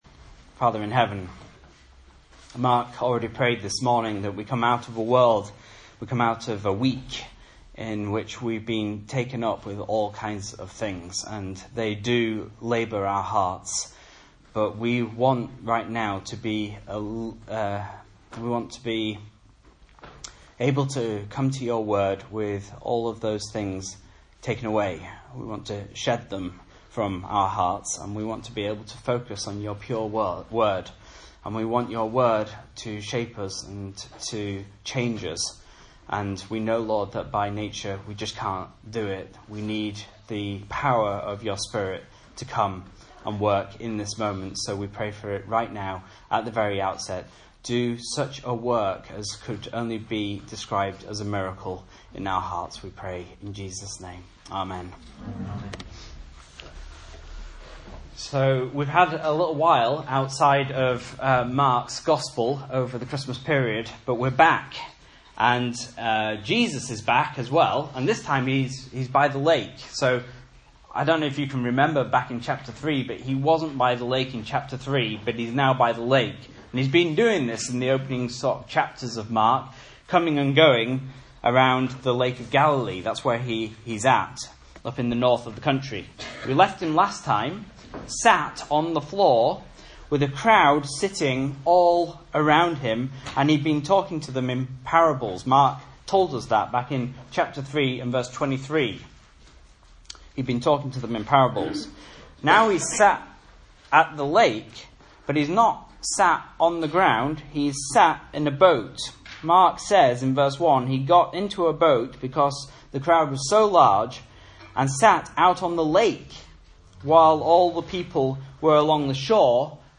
Message Scripture: Mark 4:1-20 | Listen